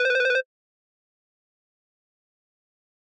フリー効果音：システム14
フリー効果音｜ジャンル：システム、システム系、第14の素材！バフを受けたときっぽい音かも？